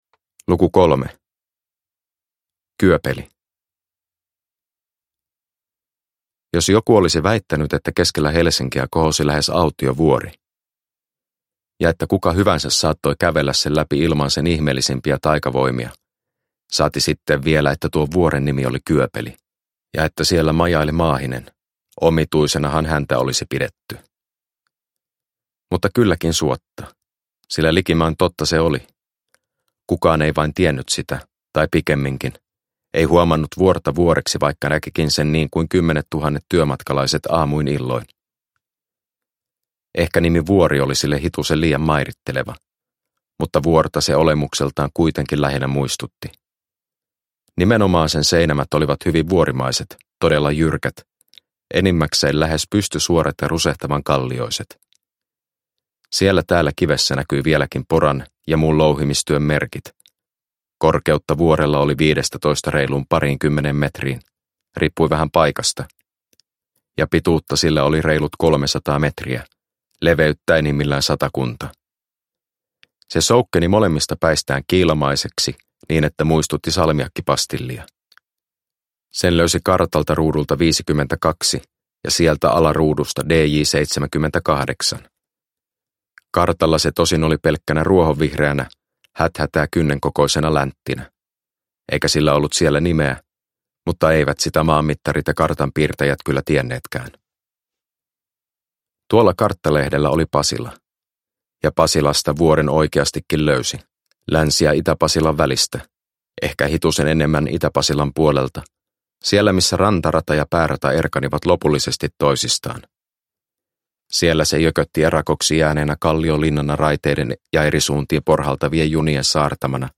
Harjunpää ja pahan pappi – Ljudbok – Laddas ner